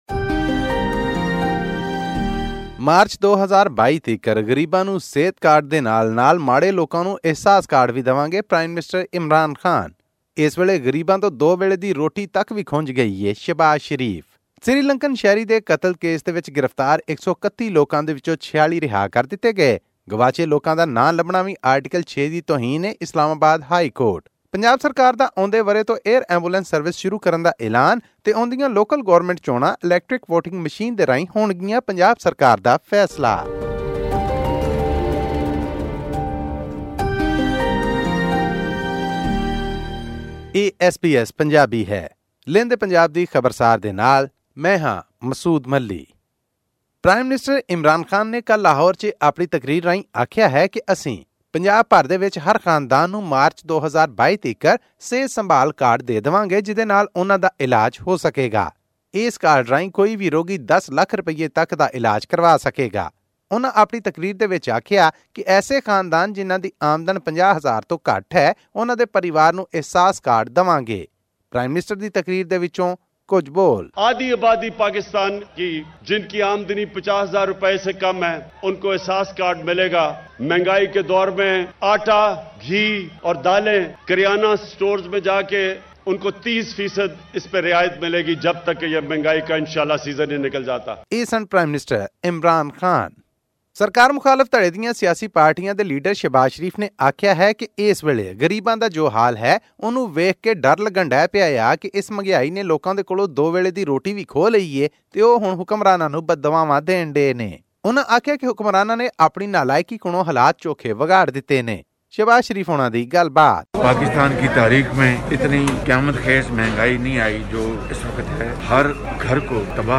Punjab Chief Minister Usman Buzdar on 13 December announced the launch of an air ambulance service. He claimed that the state would be the first province to start the service. This and more in our weekly news update from Pakistan.